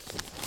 x_enchanting_scroll.3.ogg